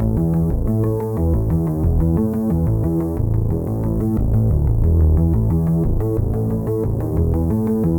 Index of /musicradar/dystopian-drone-samples/Droney Arps/90bpm
DD_DroneyArp2_90-A.wav